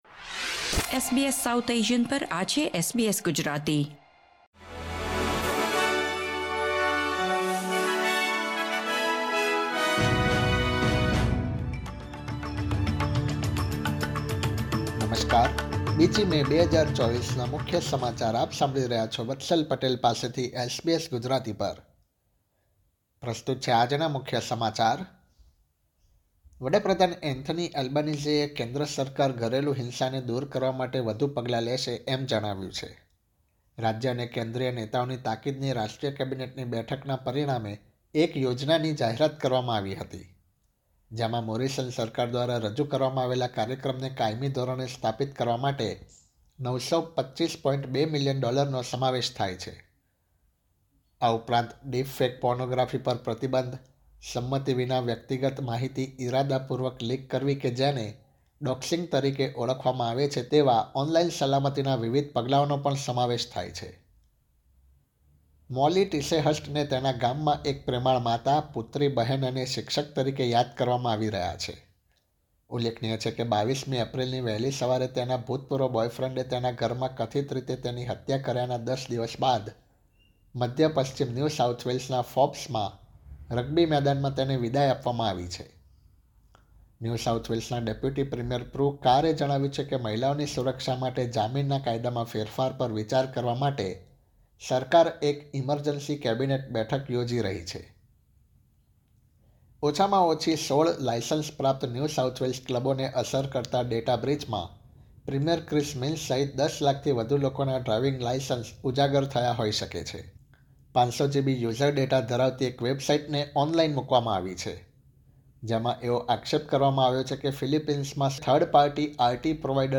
SBS Gujarati News Bulletin 2 May 2024